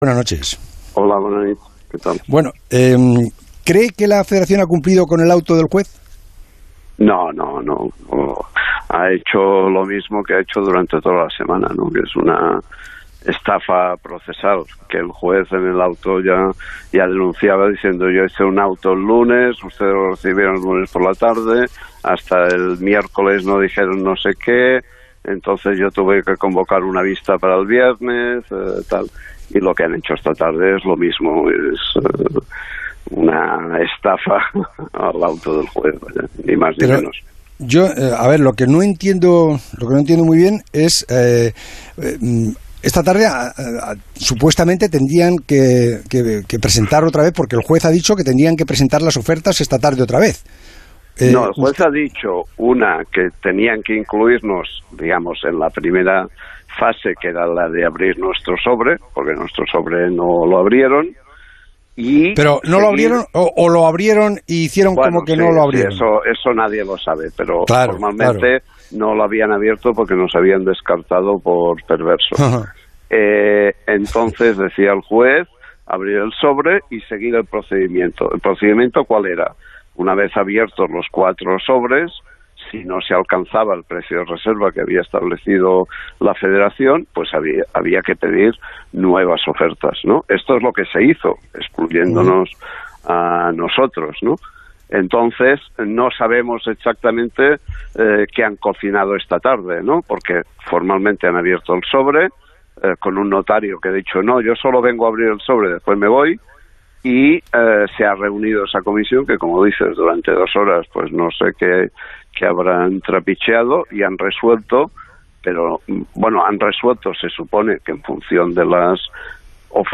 El Transistor entrevistó en la noche de este viernes a Jaume Roures, presidente de Mediapro, quien abordó la situación planteada tras el segundo auto dictado por el juez este viernes en relación con la retransmisión de la final de Copa de este sábado.